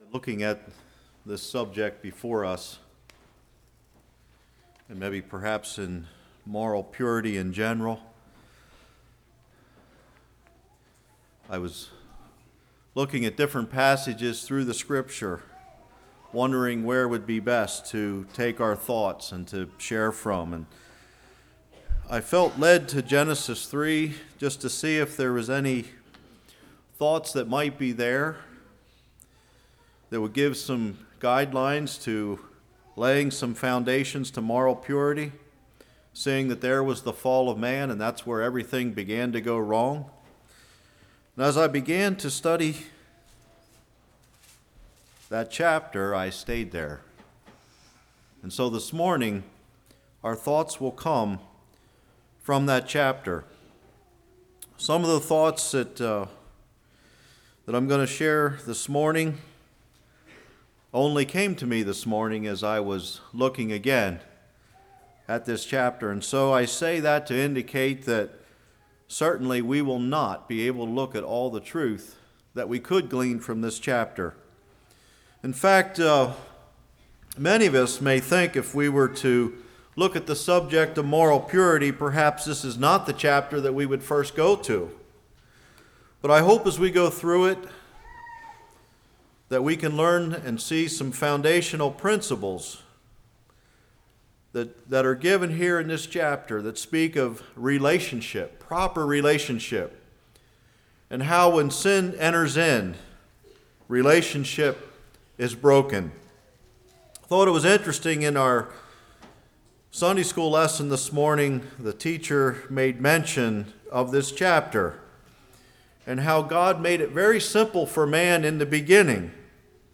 Passage: Genesis 3:1-24 Service Type: Morning